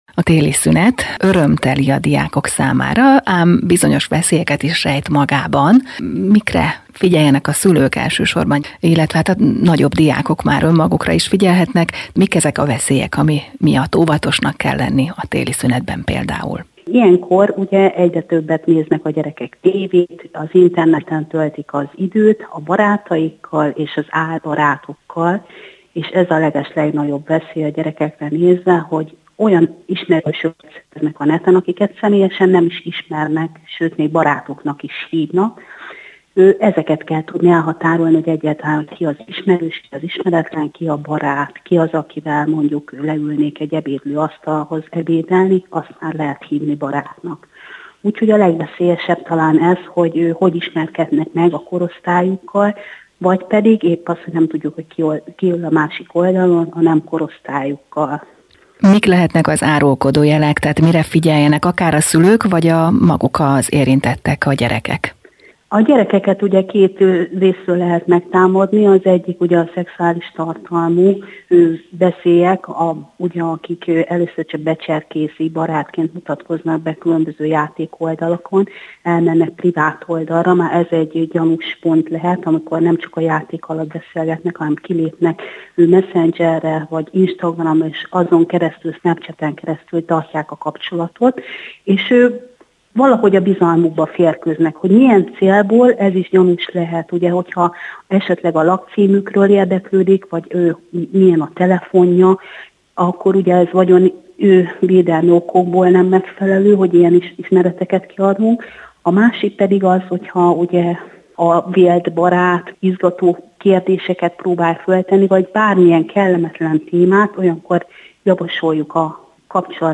beszélget az ÉrdFM 101.3 műsorában.